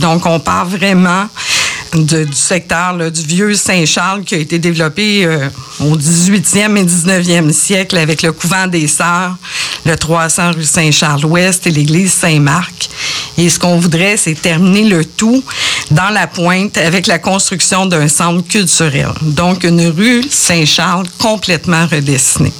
La revitalisation de la rue Saint-Charles et du secteur du métro a permis des échanges harmonieux lors d’un débat au FM 103,3, mardi.